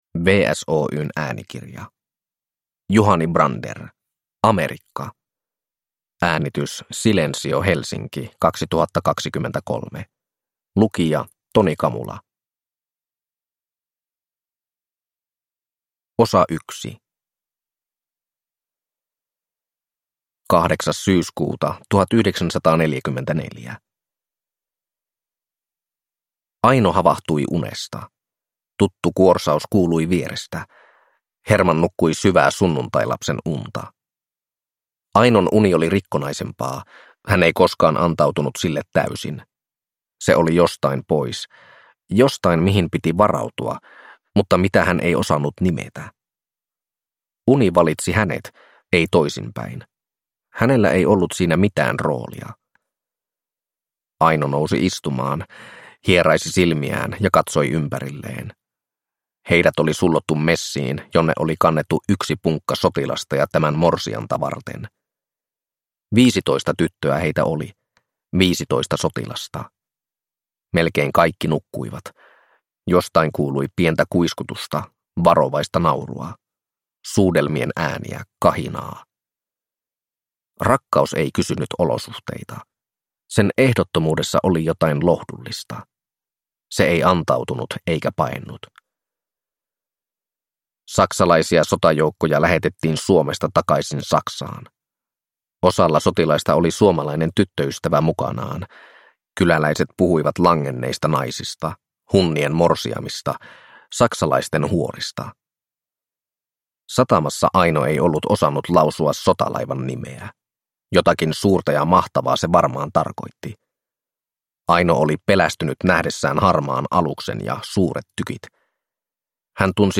Amerikka – Ljudbok – Laddas ner